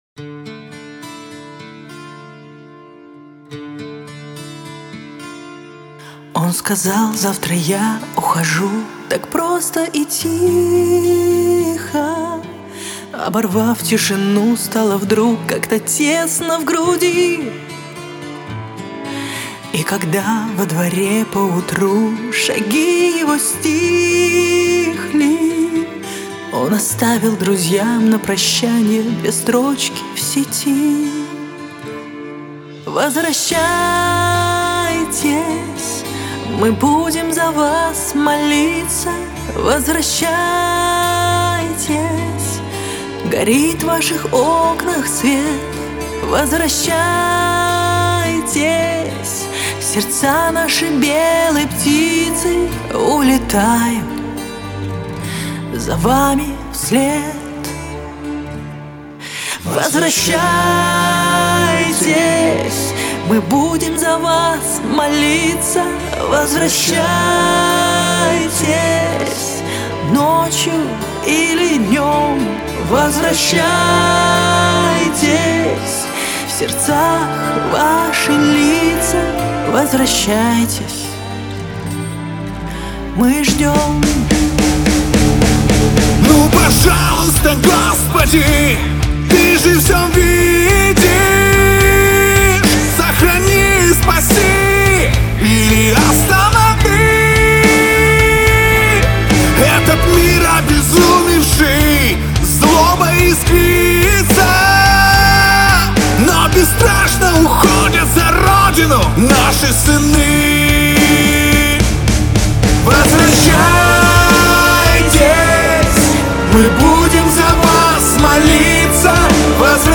• Категория: Детские песни
Армейская патриотическая музыка на 23 Февраля.